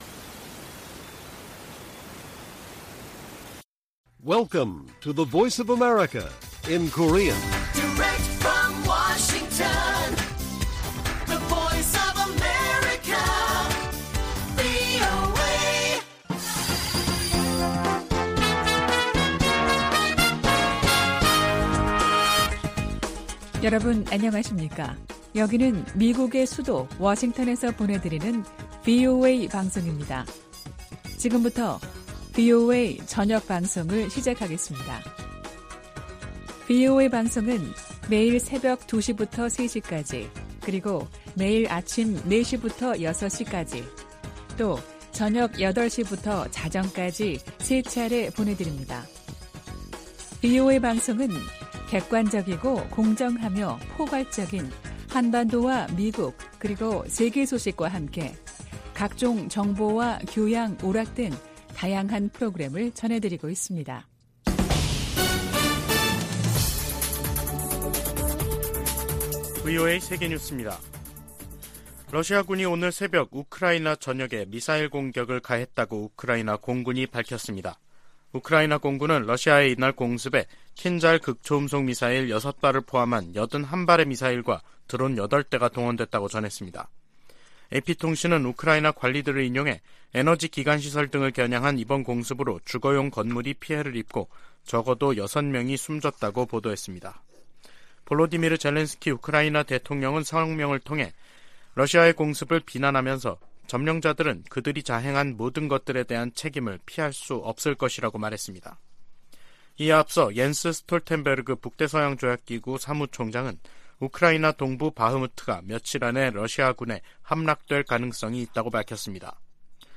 VOA 한국어 간판 뉴스 프로그램 '뉴스 투데이', 2023년 3월 9일 1부 방송입니다. 윤석열 한국 대통령이 오는 16일 일본을 방문해 기시다 후미오 총리와 정상회담을 갖는다고 한국 대통령실이 밝혔습니다. 미 국무부는 미한일 3자 확장억제협의체 창설론에 대한 입장을 묻는 질문에 두 동맹국과의 공약이 철통같다고 밝혔습니다. 권영세 한국 통일부 장관은 미래에 기초한 정책을 북한 지도부에 촉구했습니다.